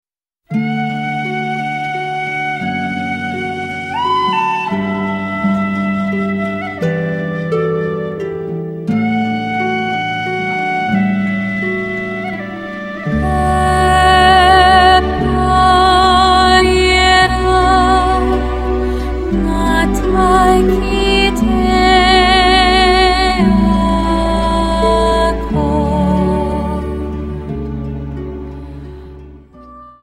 Dance: Waltz 29